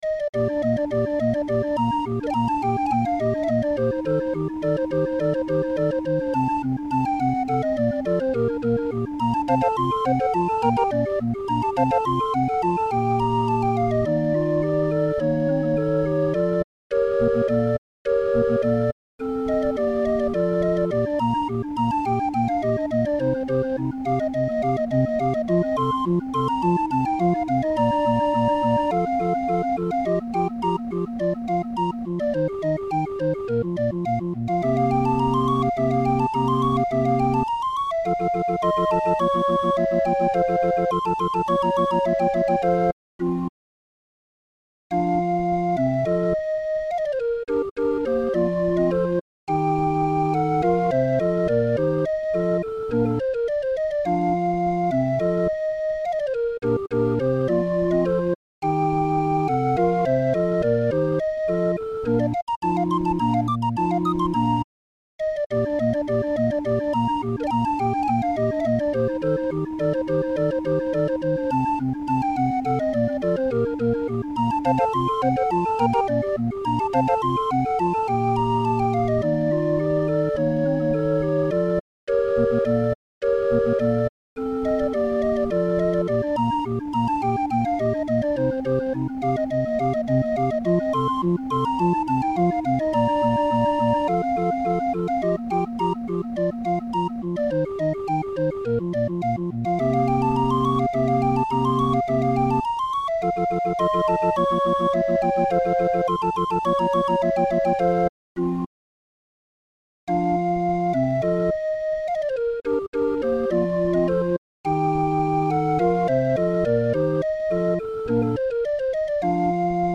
Musikrolle 31-er